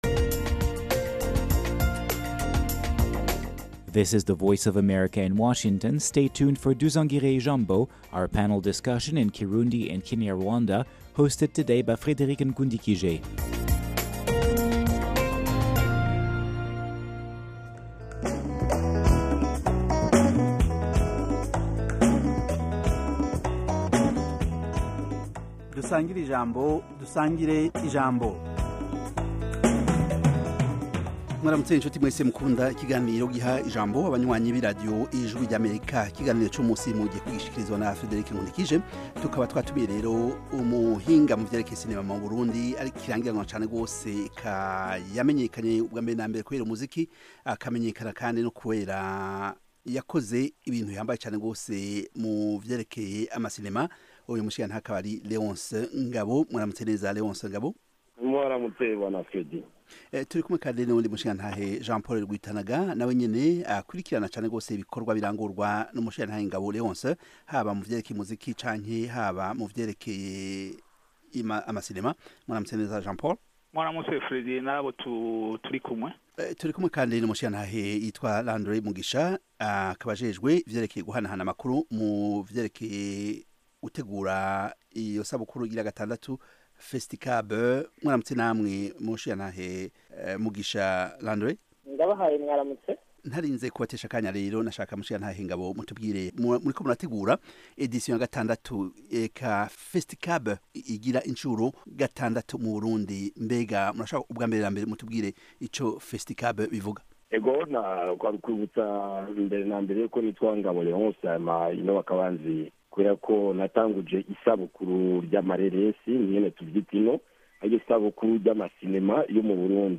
Dusangire-ijambo - Panel discussion and debate on African Great Lakes Region, African, and world issues